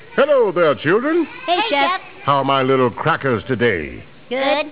- Chef greeting the children. 38kb